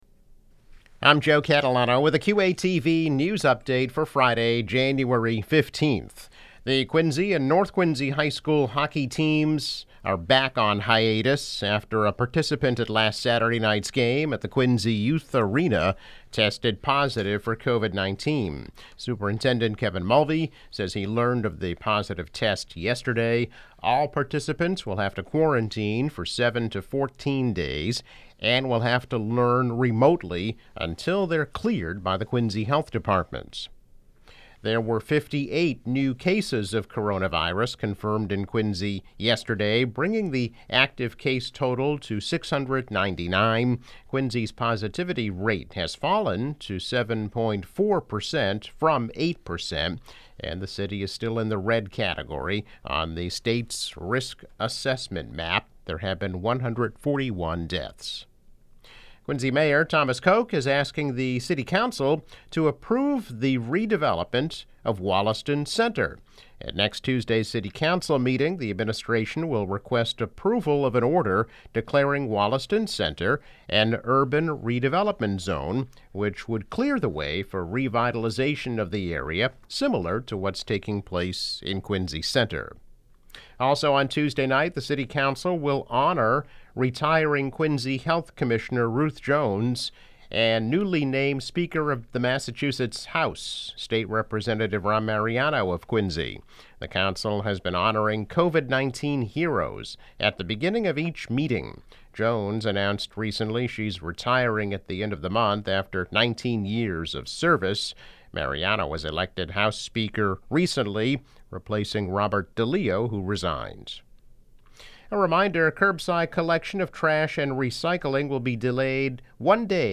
News Update - January 15, 2021